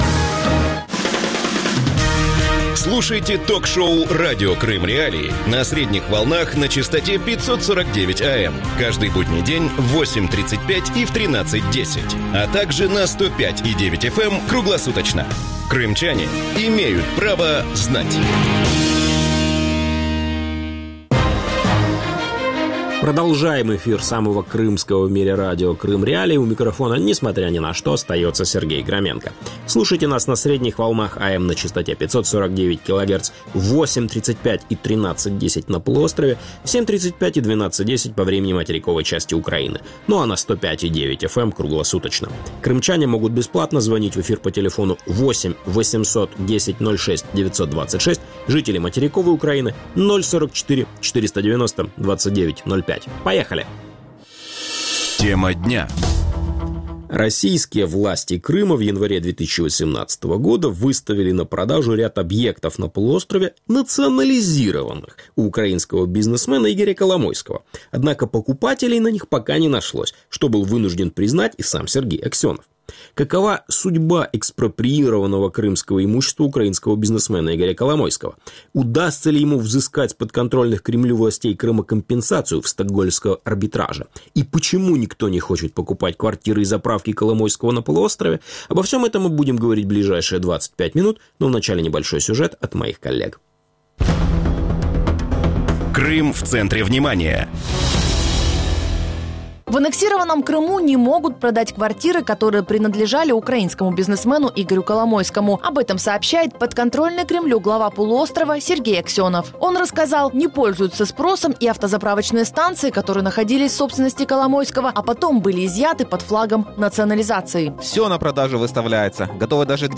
Какова судьба экспроприированного крымского имущества украинского бизнесмена Игоря Коломойского? Удастся ли ему взыскать с подконтрольных Кремлю властей Крыма компенсацию в Стокгольмском арбитраже? Почему никто не хочет покупать квартиры и заправки Коломойского на полуострове? Гости эфира